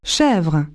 goat0m0w.wav